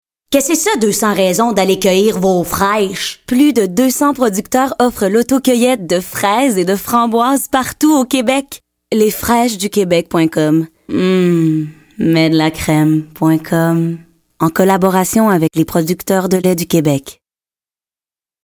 Voix de annonceur – Les Fraîches du Québec
dynamique, sourire dans la voix, inspirante